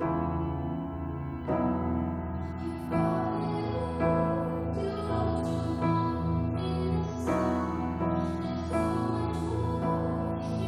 Audacity > Effect > Vocal Removal.